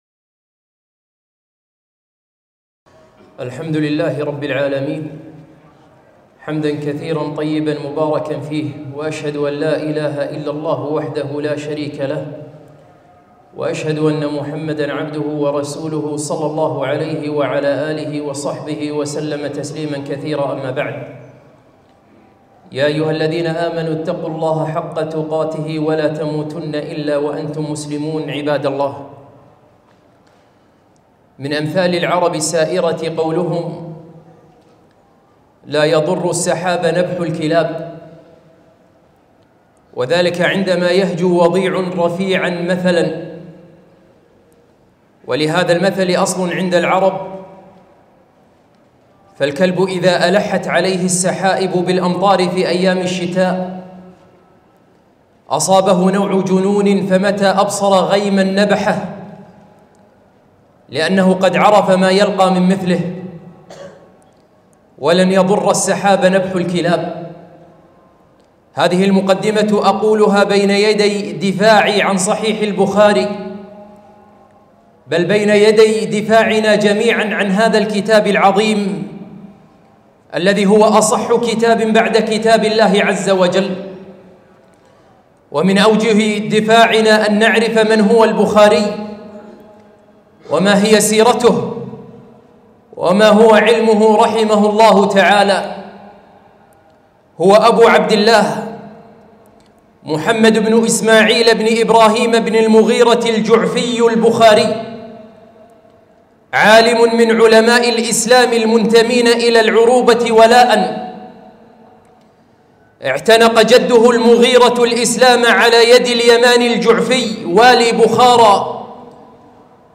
خطبة - الدفاع عن صحيح البخاري